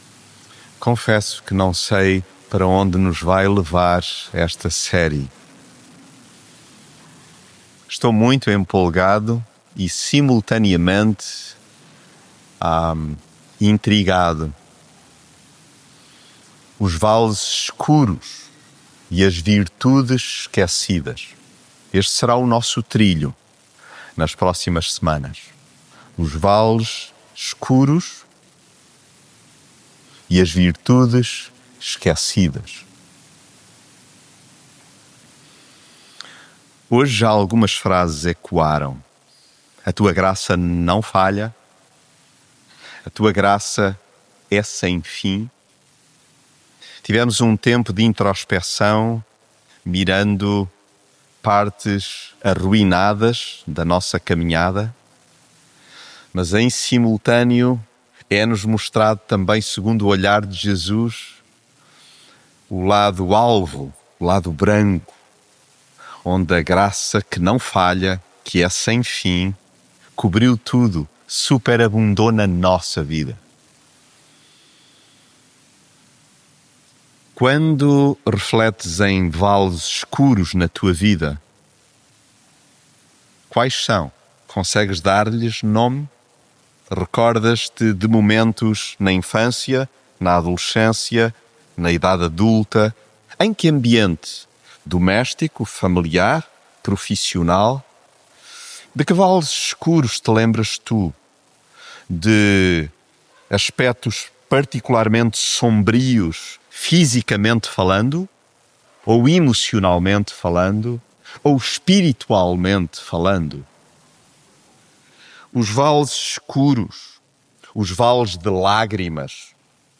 mensagem bíblica Há vales de lágrimas, de solidão, de amargura, de sofrimento atroz…